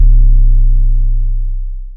YM Sub 1.wav